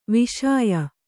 ♪ viśaya